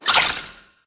Cri de Meltan dans Pokémon : Let's Go, Pikachu et Let's Go, Évoli.
Cri_0808_LGPE.ogg